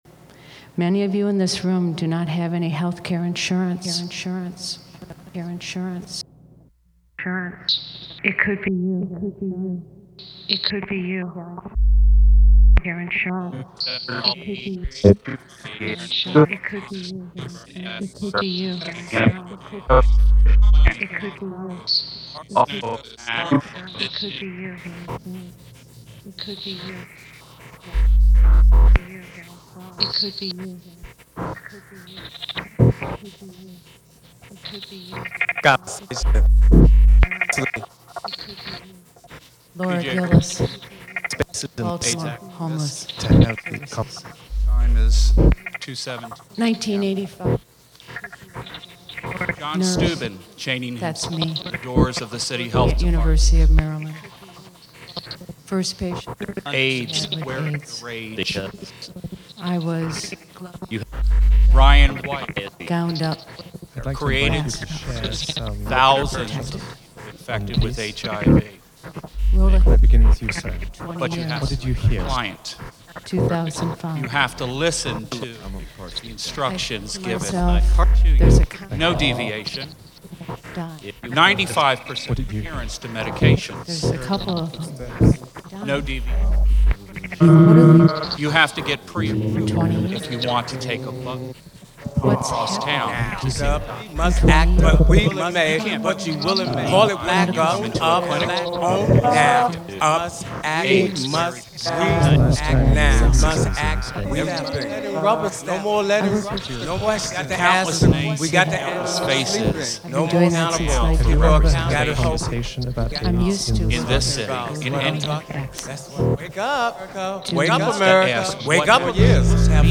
Baltimore Museum of Art, Saturday, 16 April 2005